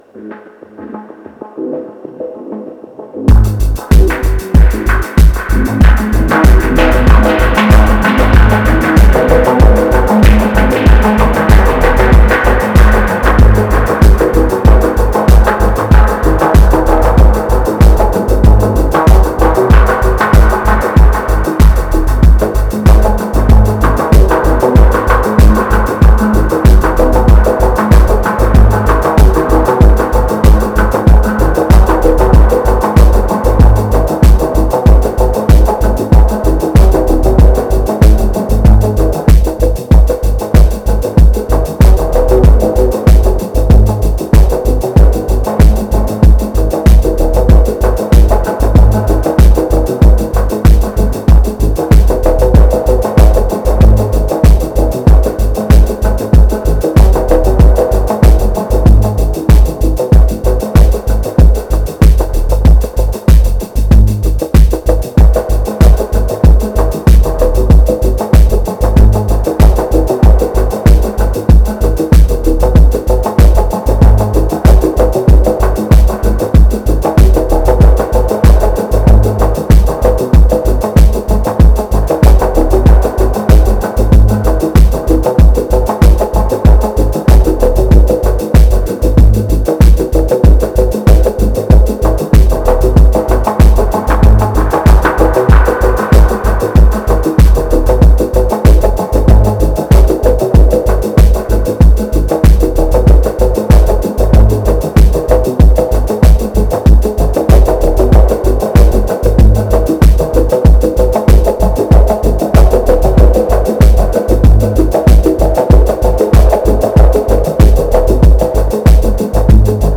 Laid Back
Its a very smooth 9 minute ambient track to cool down to.